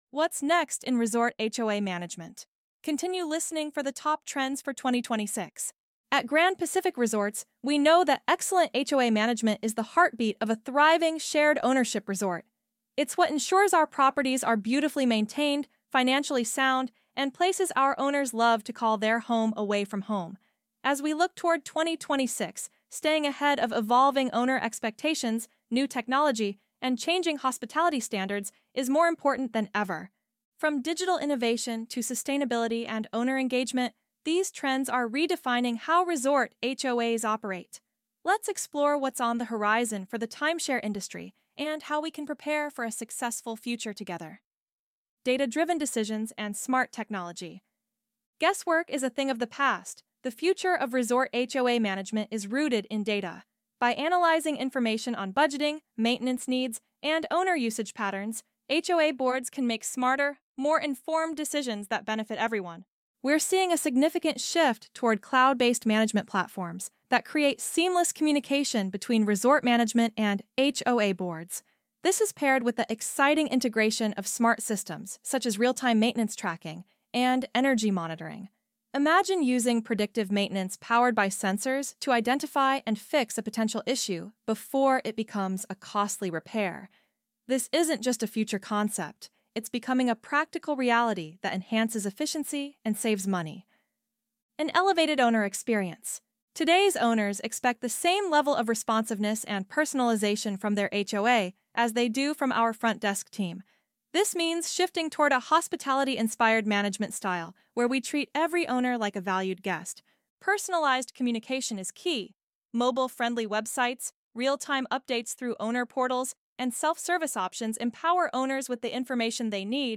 ElevenLabs_Biz_Dev_Top_Trends_for_2026.mp3